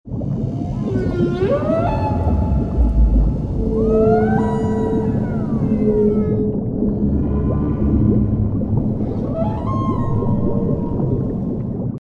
Canto-Ballenas.mp3